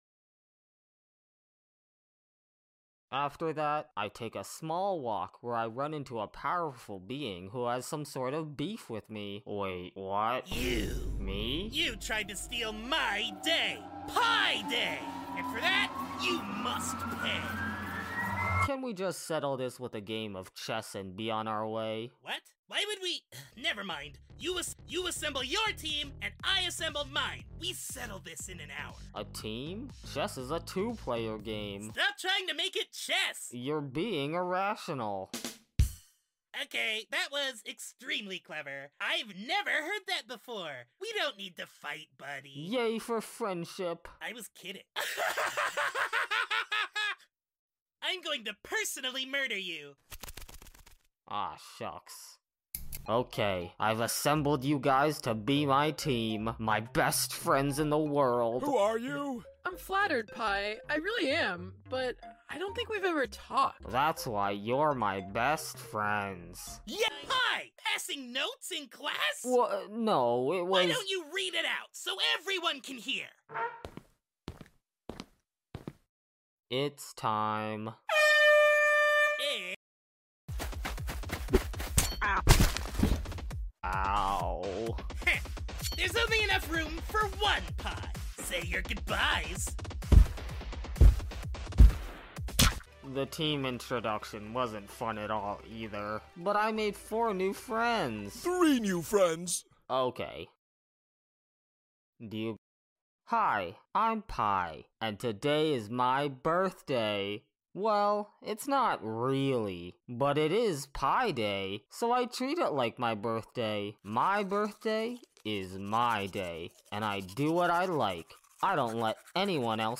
“Pi Day is Pie’s day” we all say in unison